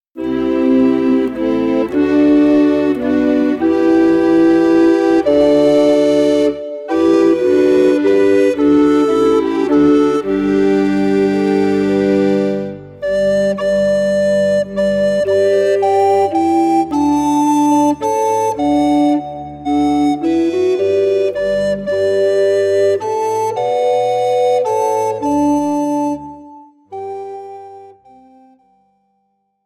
Besetzung: 1-2 Sopranblockflöten